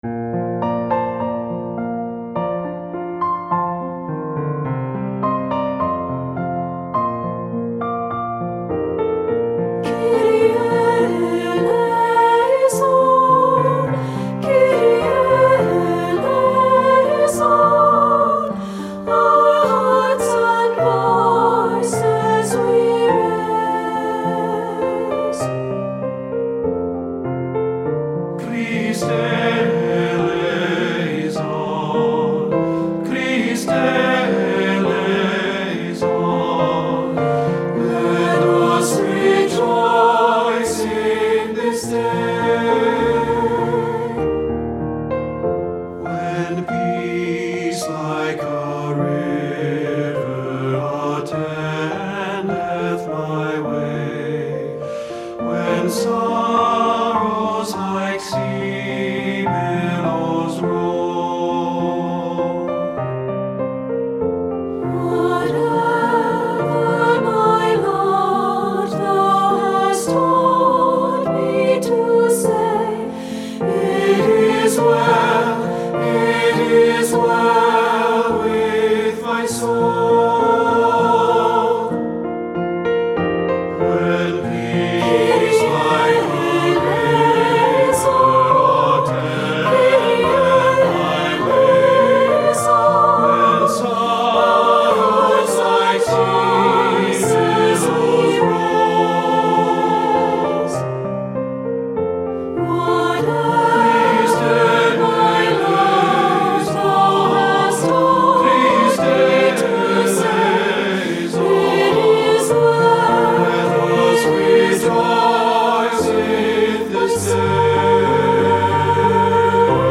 • Alto
• Piano
Studio Recording
Ensemble: Unison and Two-Part Chorus
Accompanied: Accompanied Chorus